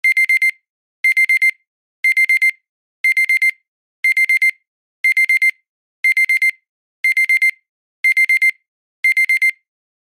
lg-timer_24592.mp3